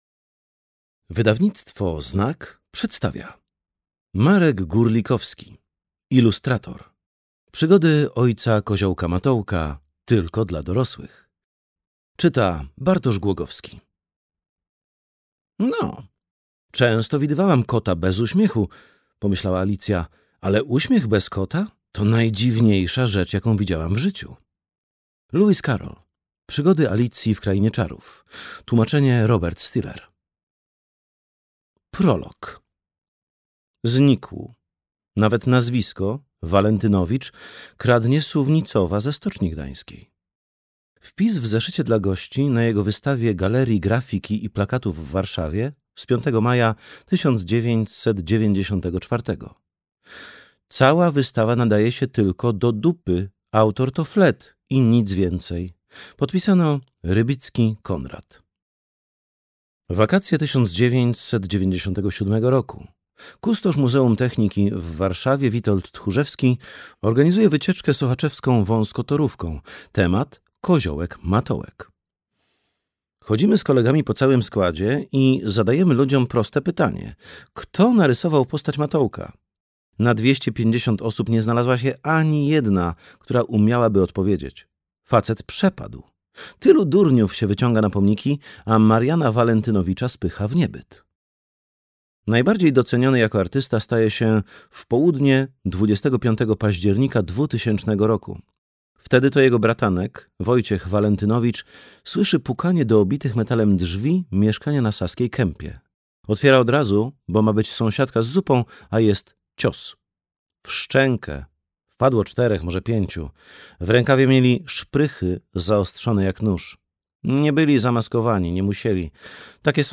Ilustrator. Przygody ojca Koziołka Matołka dla dorosłych - Górlikowski Marek - audiobook